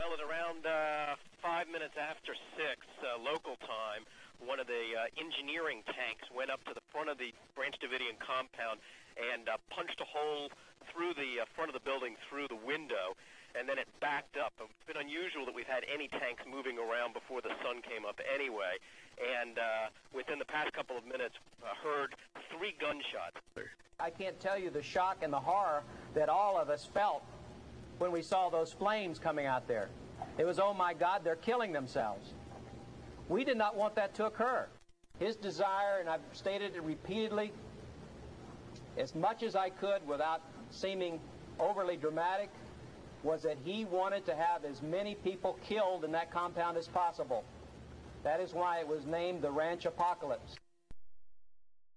I listened to NPR news every morning and this was the lead item.